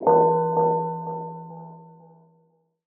UIMvmt_Game Over Ghostly Haunted 01.wav